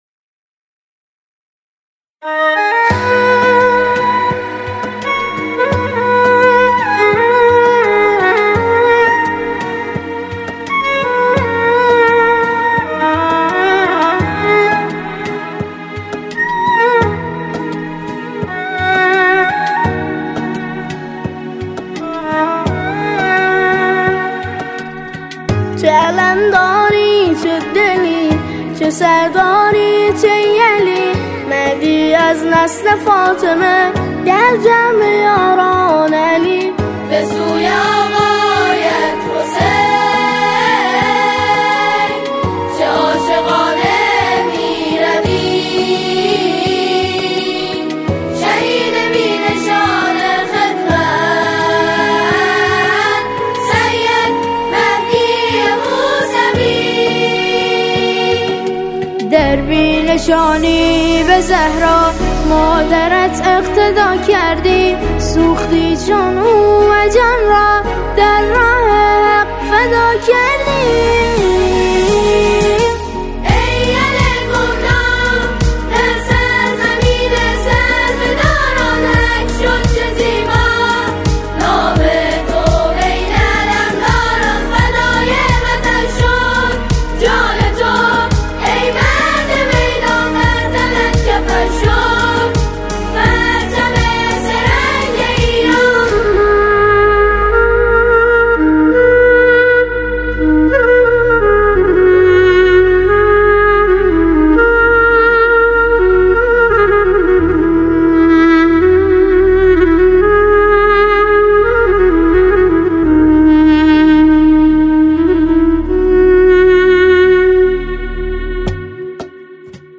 سرود شهید رئیسی